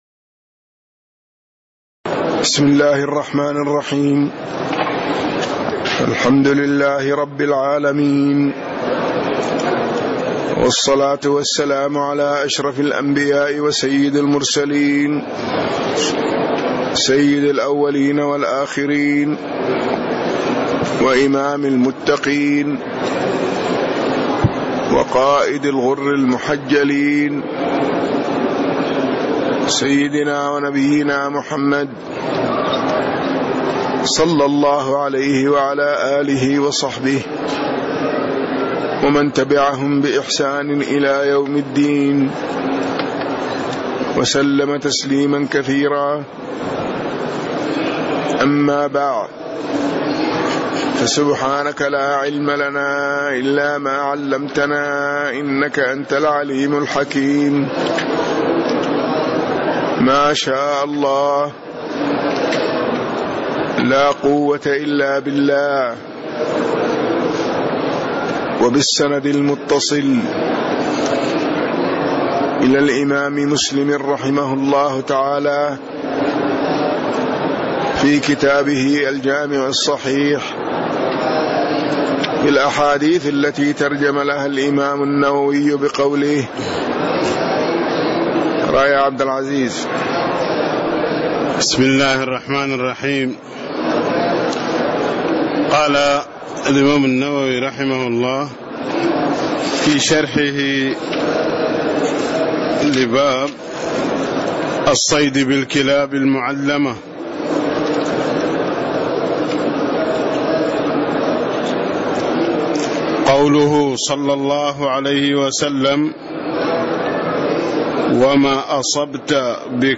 تاريخ النشر ٣ جمادى الأولى ١٤٣٦ هـ المكان: المسجد النبوي الشيخ